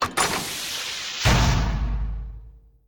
DoorClose3.ogg